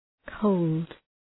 {kəʋld}
cold.mp3